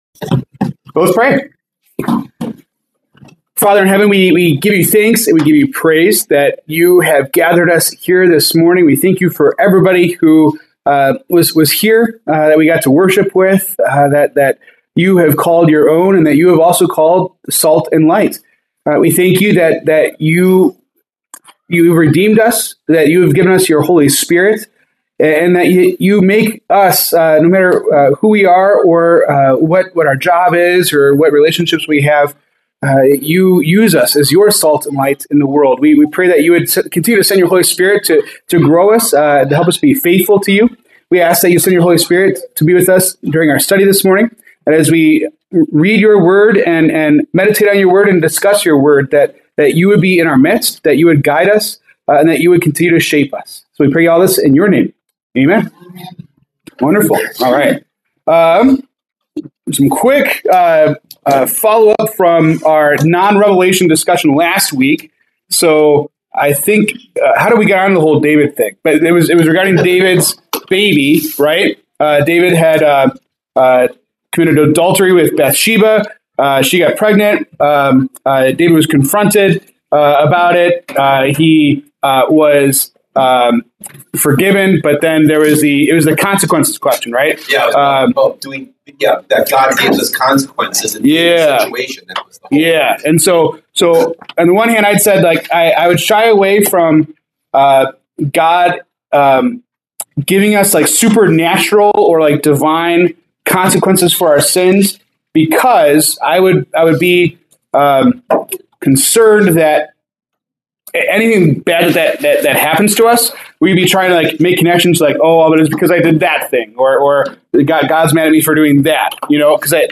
February 8, 2026 Bible Study